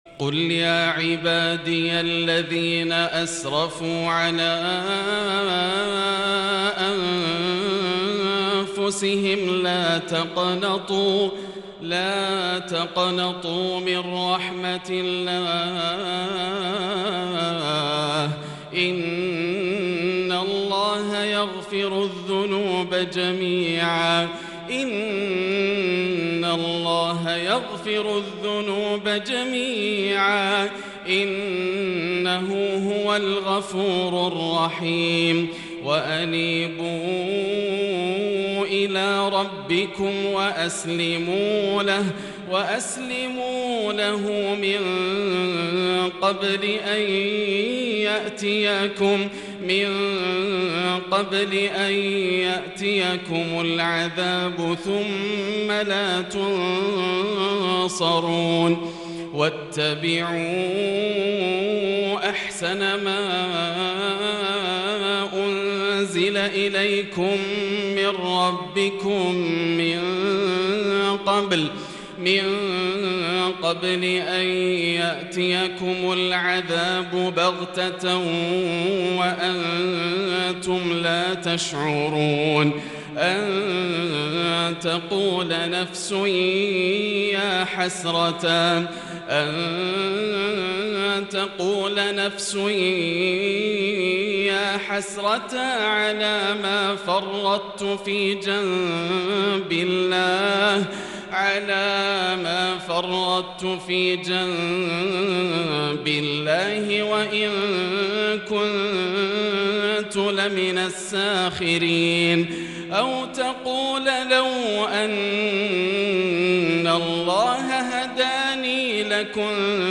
وما قدروا الله حق قدره | الشيخ ياسر الدوسري يبكي 😢 ويتأثر من خشية الله - 14 ذو الحجة 1443هـ